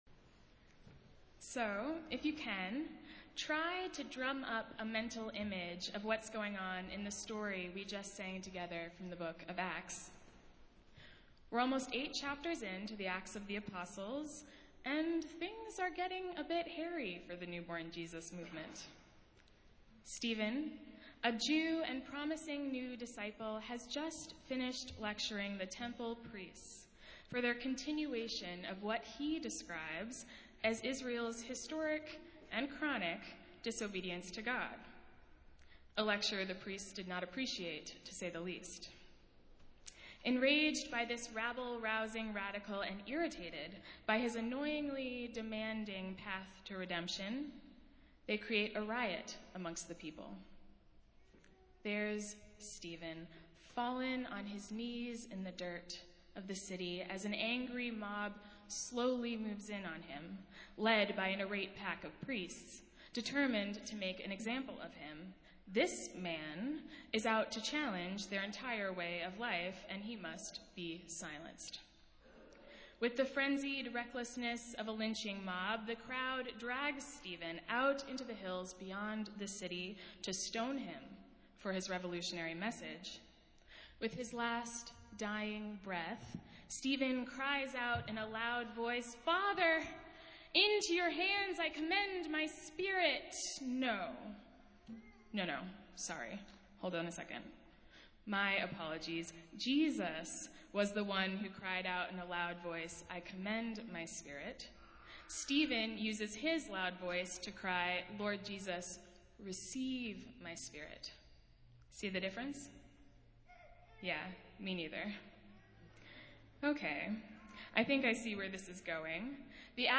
Festival Worship - Sixth Sunday in Lent